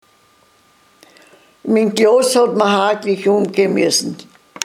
Mundart: Wuderscher Dialekt
Man hörte dabei eine gedehnte „ua“ Aussprache.
Unser Budaörser Heimatmuseum besitzt eine Sammlung von Tonaufnahmen in wunderbar gesprochenem Wuderscher Dialekt, den wir in einer Hörprobe präsentieren: